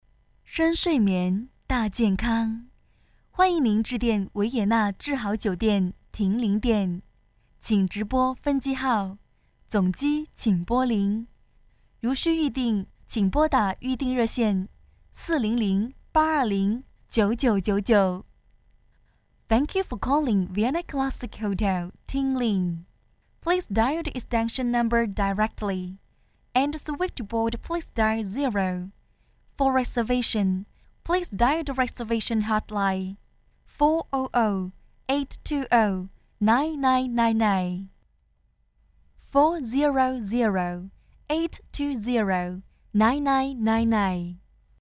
女43号配音师
彩铃